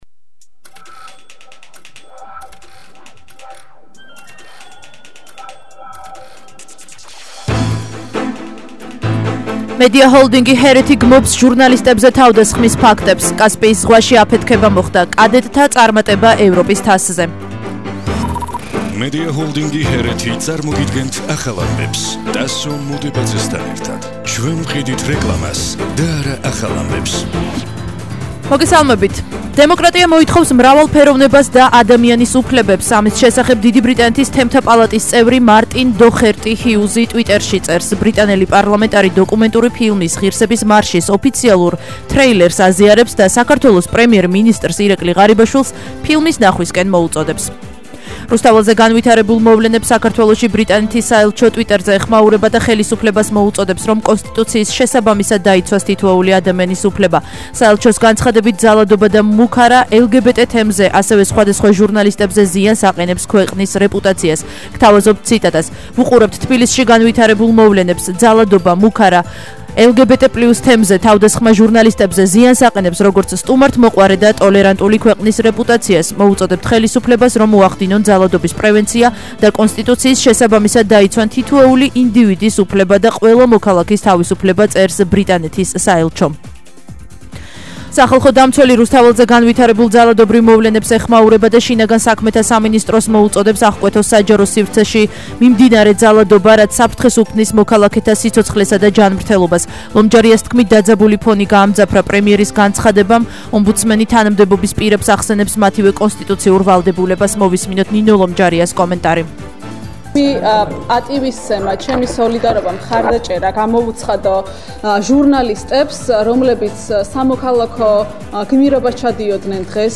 ახალი ამბები 16:00 საათზე –05/07/21